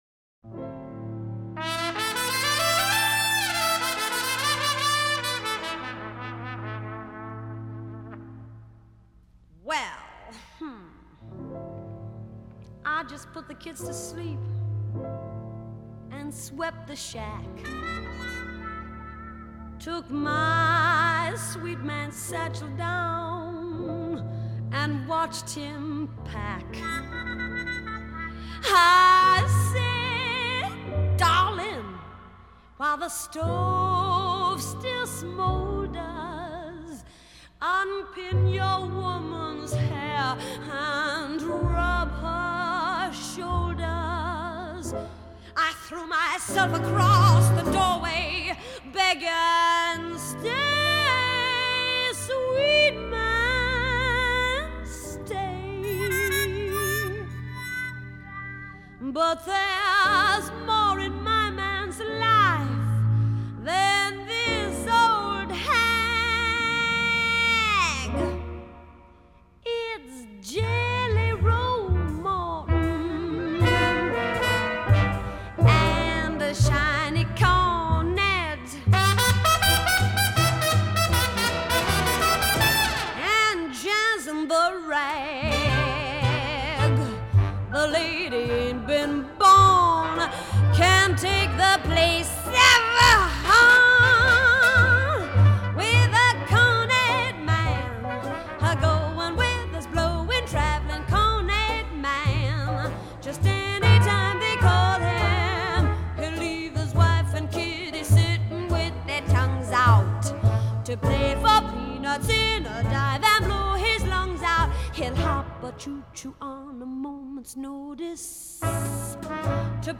1964   Genre: Musical   Artist